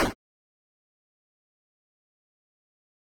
cuttree.wav